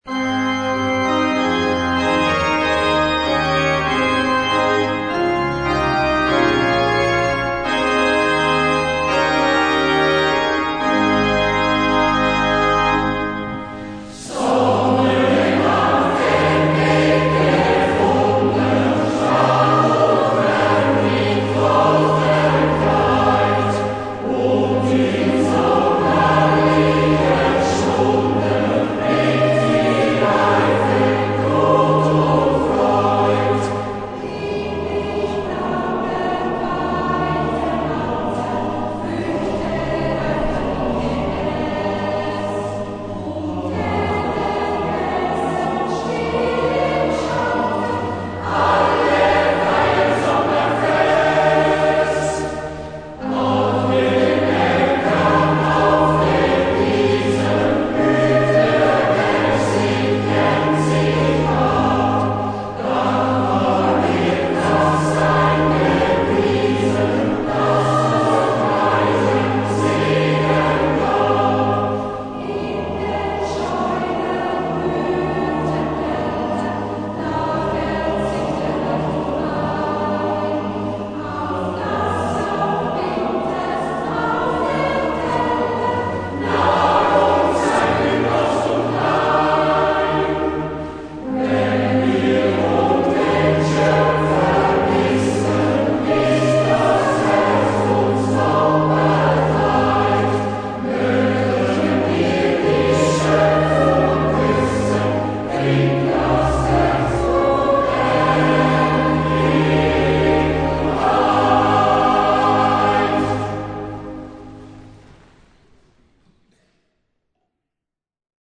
für einen gemischten Chor mit Orgel
Insgesamt reichten 27 Komponisten Werke für einen "gemischten Chor im Volkston" ein.
"Der Sommer" (Aufnahme von der Uraufführung in der Pfarrkirche St. Martin, Altdorf, vom 27.